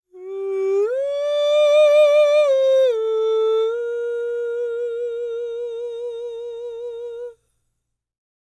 Index of /90_sSampleCDs/ILIO - Vocal Planet VOL-3 - Jazz & FX/Partition F/2 SA FALSETO